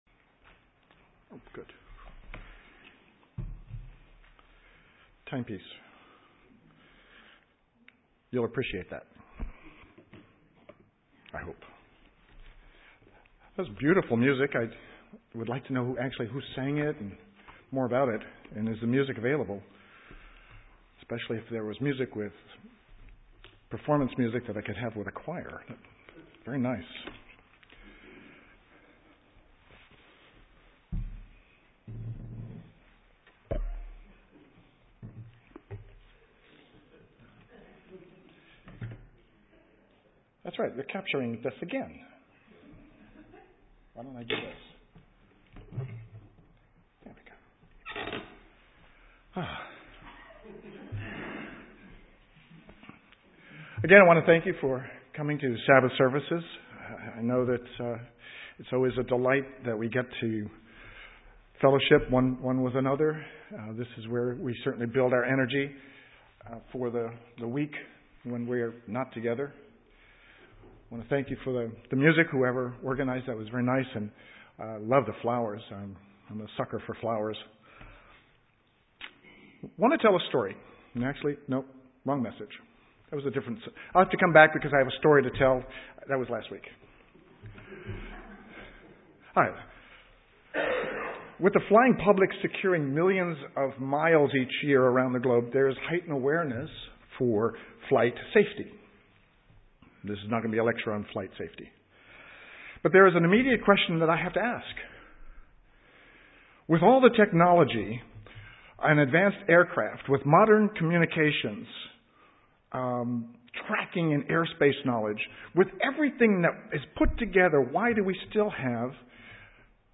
A Christian living sermon.